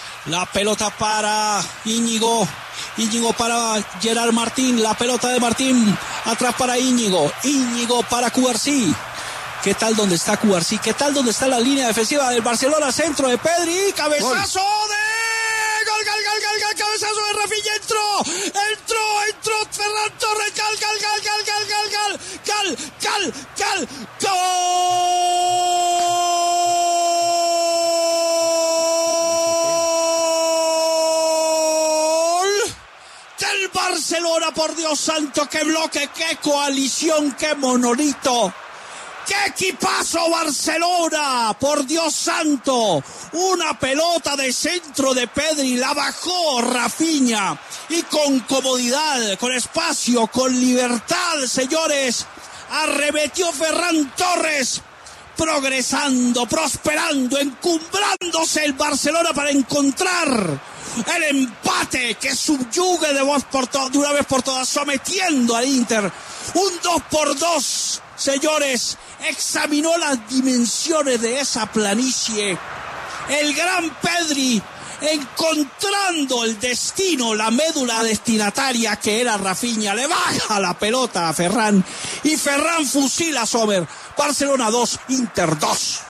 “Qué coalición, qué monolito, qué equipazo”: Así narró Martín de Francisco el segundo gol del Barca
Así narró el gol Martín De Francisco: